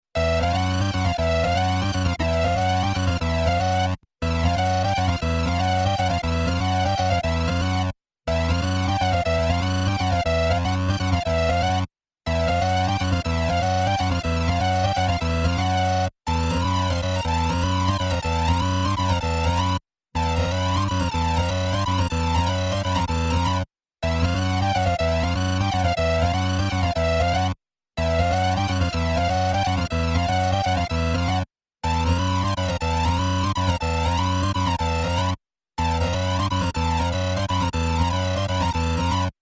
8 bit Gaming Musik
Tempo: schnell / Datum: 15.08.2019